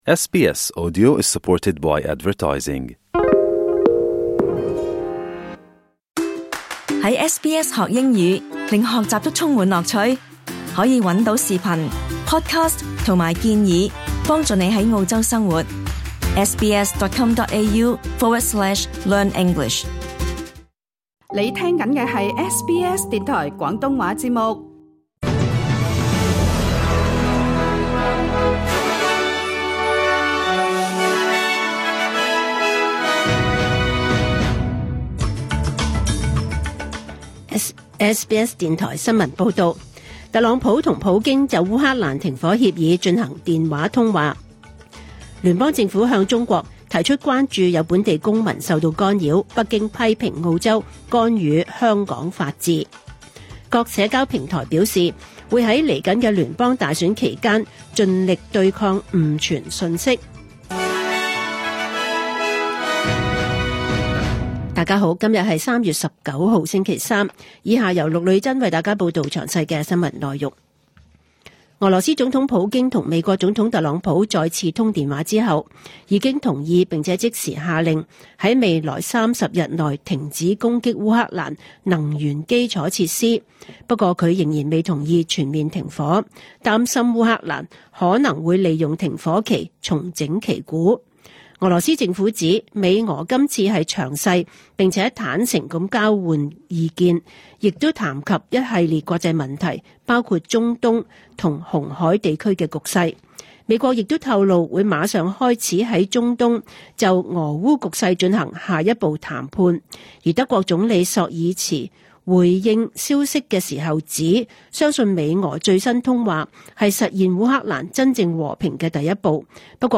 2025 年 3 月 19 日 SBS 廣東話節目詳盡早晨新聞報道。